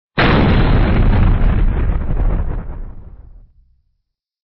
Explosion Boba Fett Sound Effect Free Download